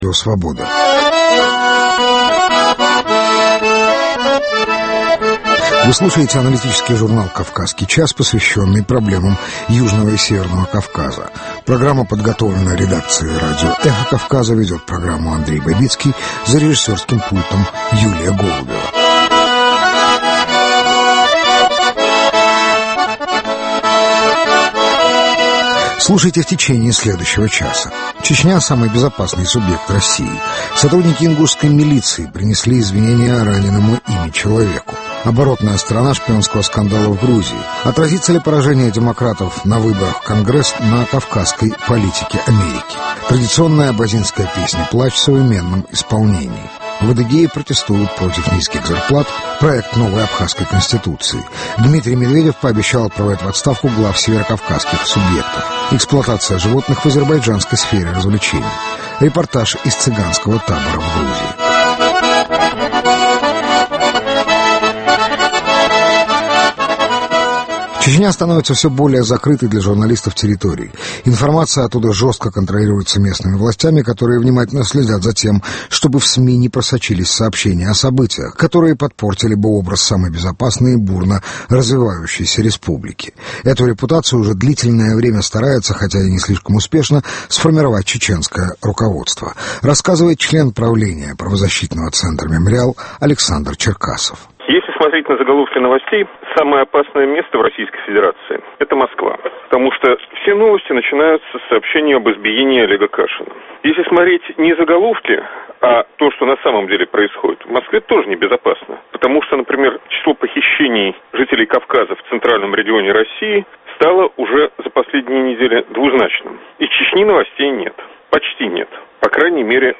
Традиционная абазинская песня-плач в современном исполнении.
Репортаж из цыганского табора в Грузии.